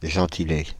Ääntäminen
Synonyymit démonyme ethnonyme Ääntäminen Paris: IPA: [ʒɑ̃.ti.le] France (Île-de-France): IPA: /ʒɑ̃.ti.le/ Haettu sana löytyi näillä lähdekielillä: ranska Käännös Substantiivit 1. popolnomo Suku: m .